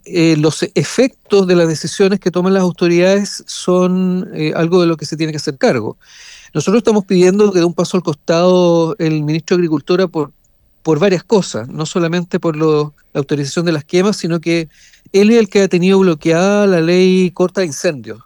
Una situación donde ya se buscan responsabilidades políticas y por lo mismo el diputado y candidato presidencial del Partido Ecologista Verde, Félix González, dijo que el ministro de Agricultura, Esteban Valenzuela, debe dar un paso al costado.